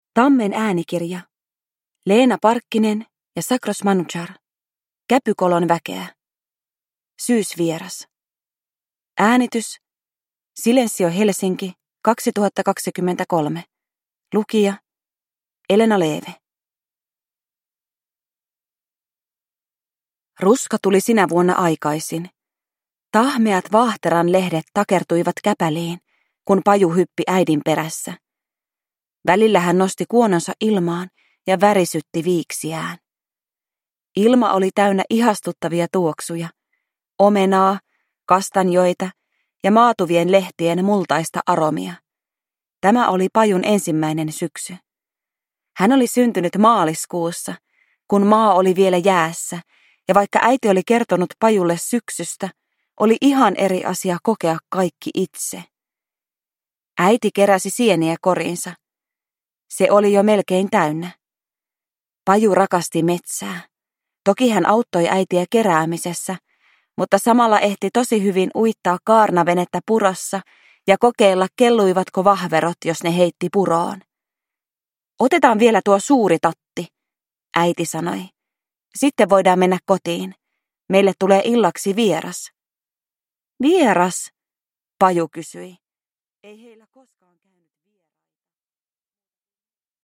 Uppläsare: Elena Leeve